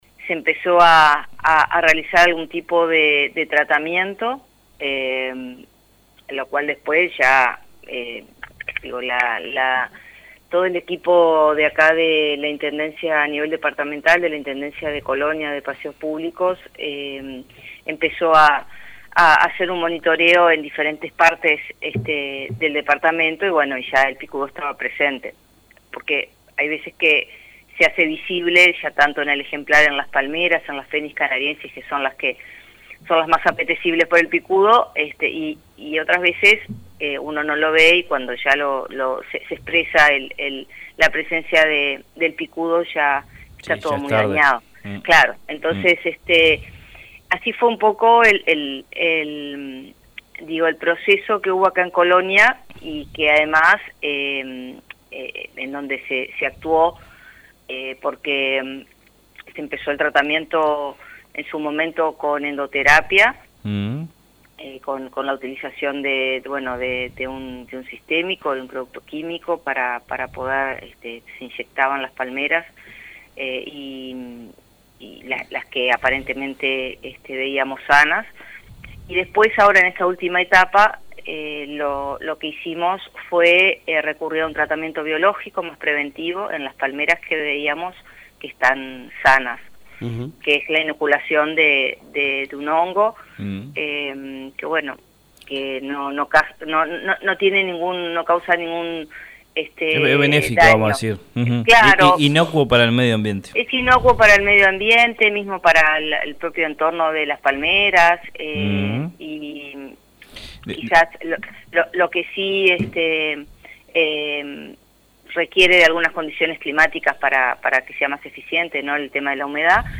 explicó detalles del tratamiento realizado en diálogo con el programa Sábado Rural de Sacramento FM.